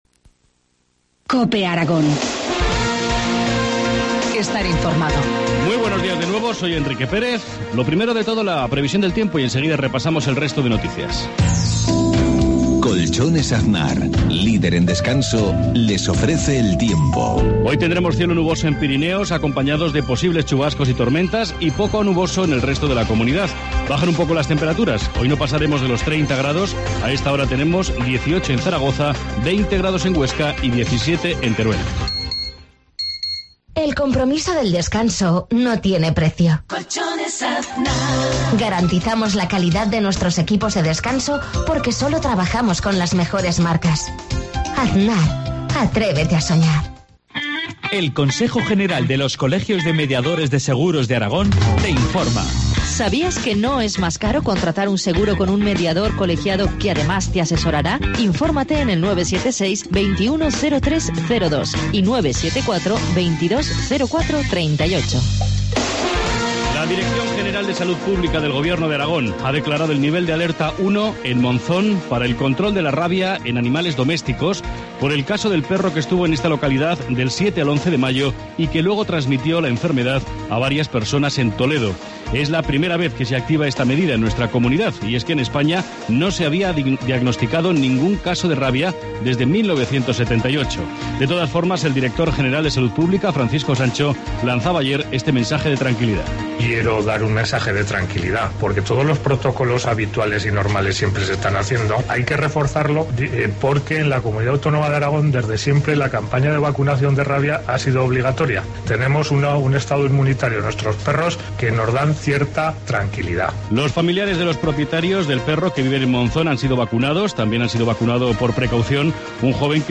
Informativo matinal, 14 junio, 7,53 horas